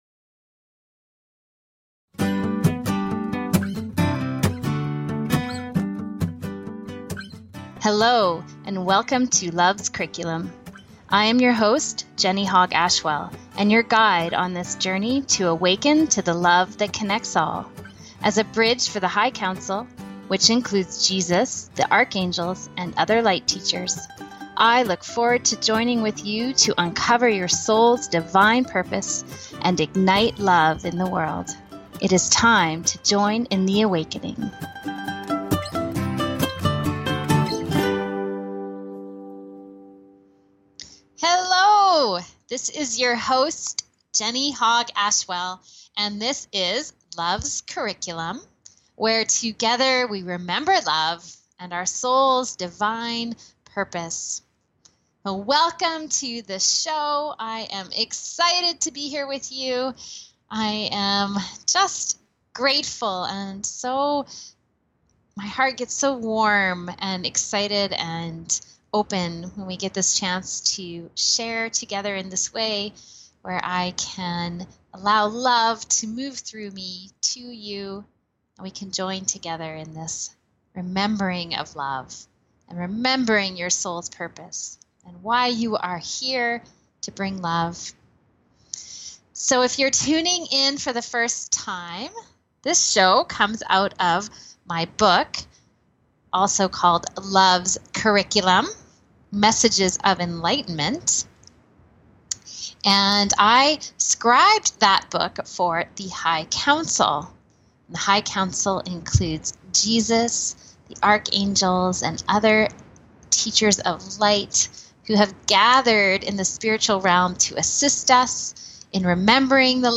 Talk Show Episode, Audio Podcast, Loves Curriculum and The Nature of Love on , show guests , about The Nature of Love, categorized as Health & Lifestyle,Kids & Family,Philosophy,Psychology,Self Help,Spiritual